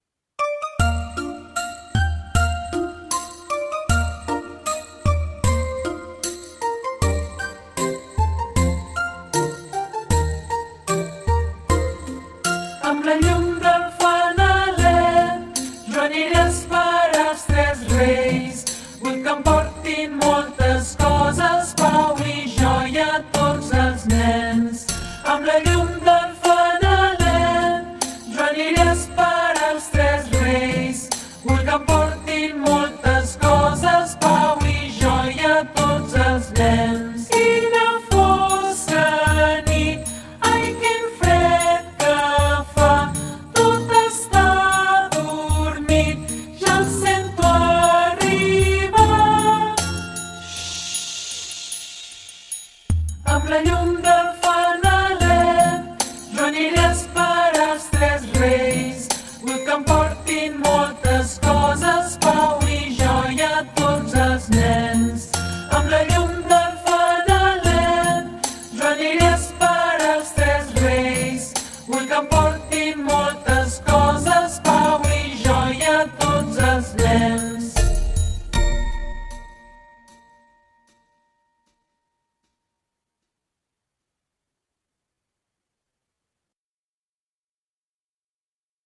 Amb-la-llum-del-fanalet-baixa-i-lenta.mp3